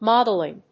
音節mód・el・ing 発音記号・読み方
/‐d(ə)lɪŋ(米国英語), ˈmɑ:dʌˌlɪŋ(英国英語)/